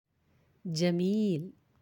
(jamil)
How to say Beautiful in Arabic